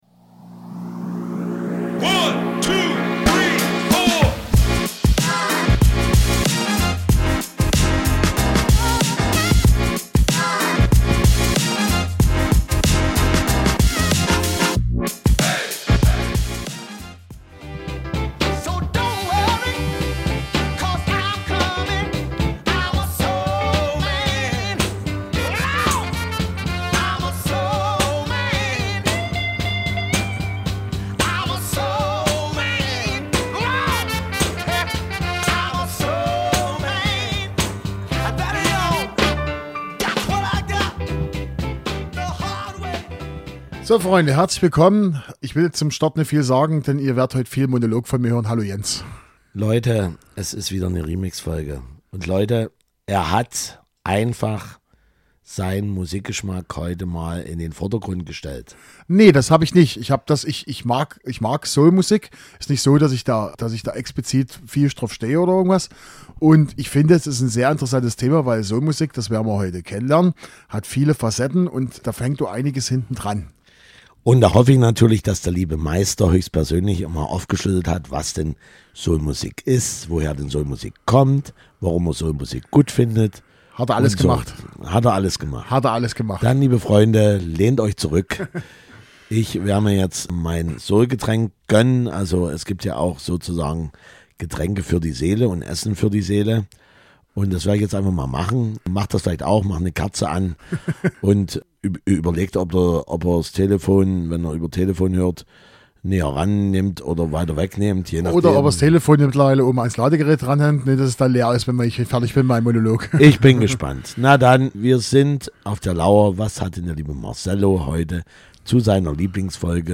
In dieser Ausgabe wird extrem deutlich, wie breit und vielfältig Soul ist. Mit dabei haben wir den klassischen Soul, RnB der 90er, Pop Soul der 80er und deutschen Soul.